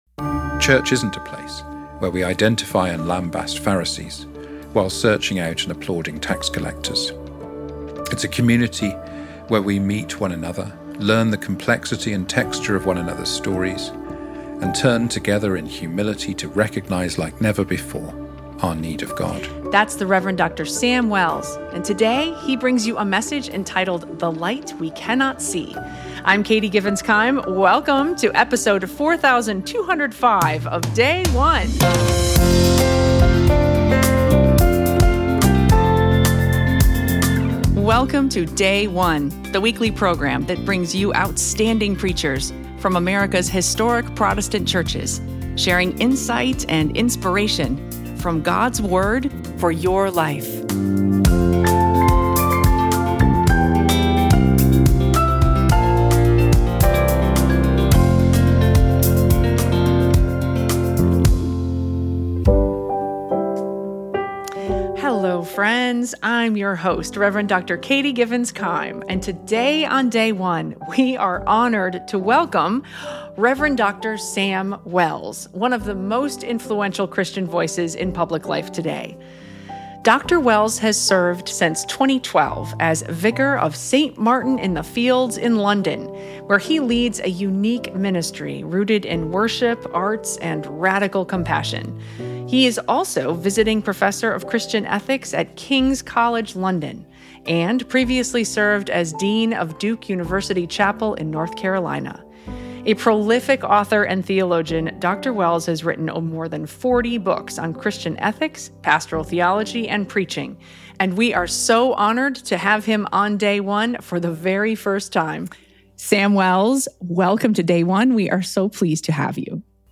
preaches “The Light We Cannot See” on Luke 18:9–14.